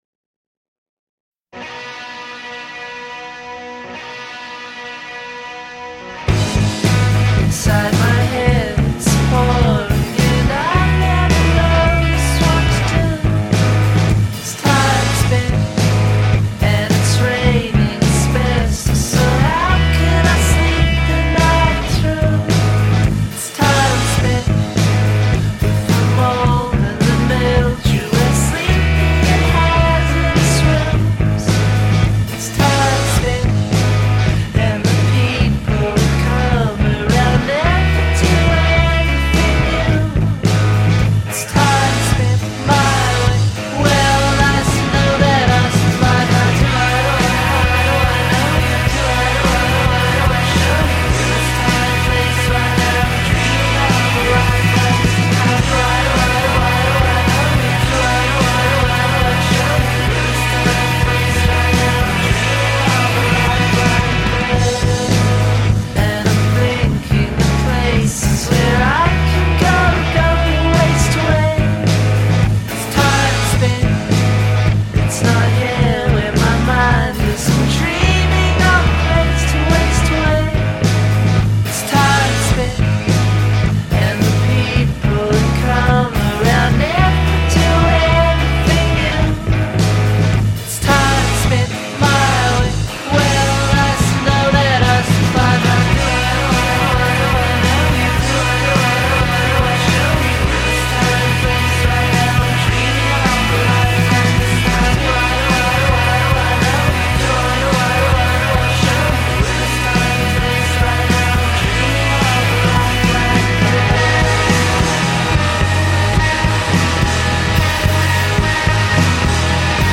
Ci sono riff pesanti e chitarre impetuose.
dal ritornello irresistibile.